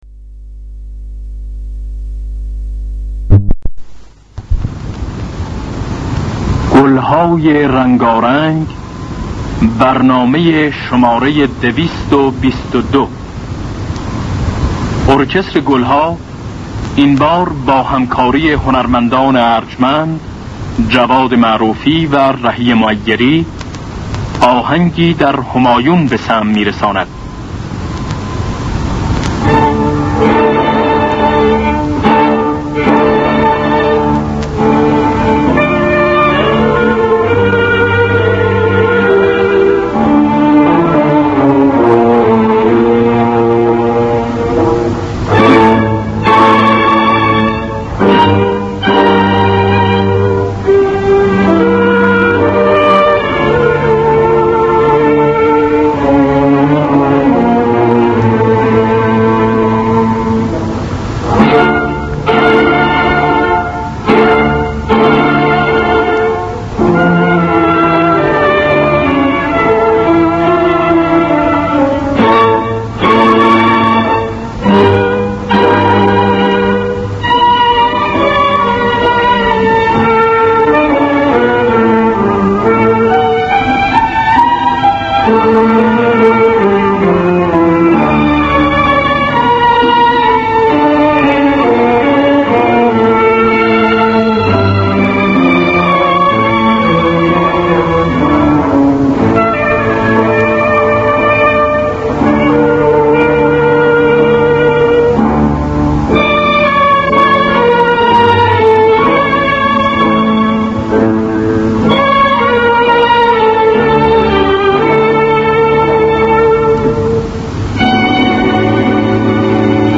گلهای رنگارنگ ۲۲۲ - همایون
خوانندگان: بنان نوازندگان: جواد معروفی